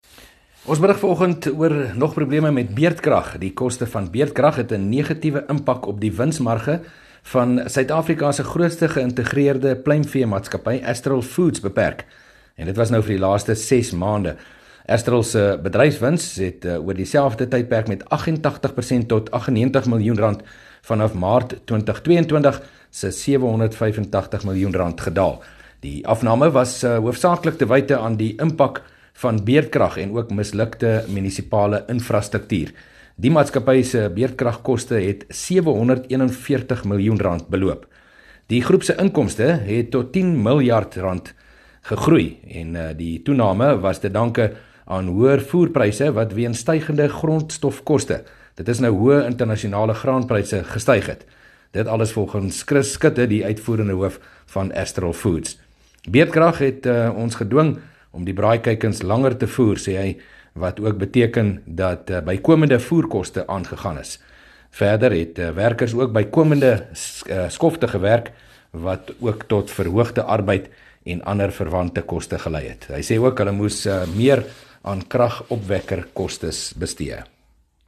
berig oor die negatiewe invloed van beurtkrag op die landbou industrie